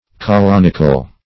Search Result for " colonical" : The Collaborative International Dictionary of English v.0.48: Colonical \Co*lon"i*cal\, a. [L. colonus husbandman.] Of or pertaining to husbandmen.